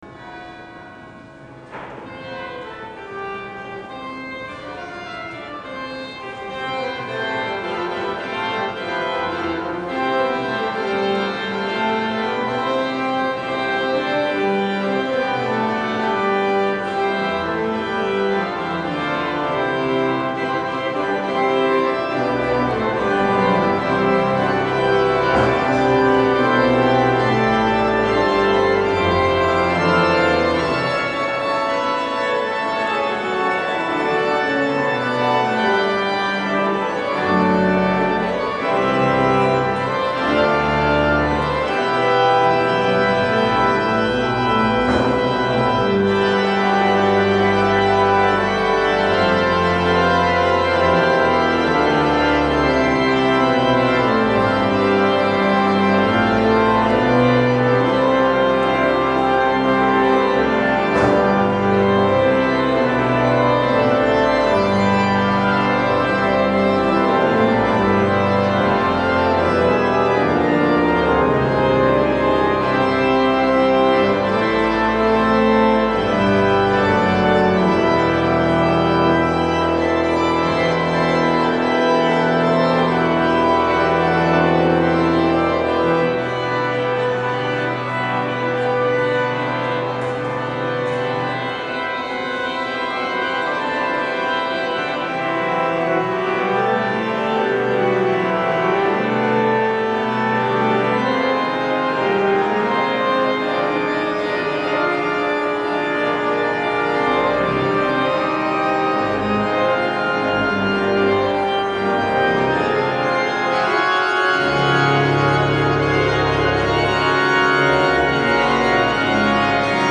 Postlude